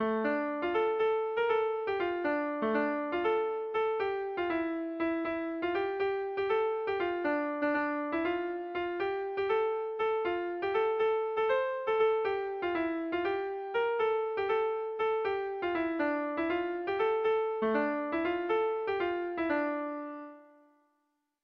Air de bertsos - Voir fiche   Pour savoir plus sur cette section
Zortziko handia (hg) / Lau puntuko handia (ip)
ABDE